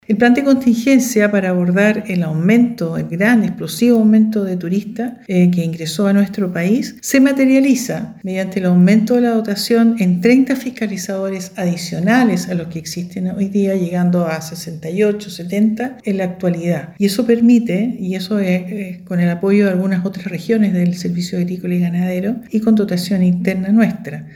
En ese contexto, se aumentó la dotación de jueves a domingo, logrando abrir 25 casetas para vehículos particulares, bajando notablemente los tiempos de espera en la frontera. Sobre esto, habló la directora Regional del Servicio Agrícola y Ganadero, Astrid Tala.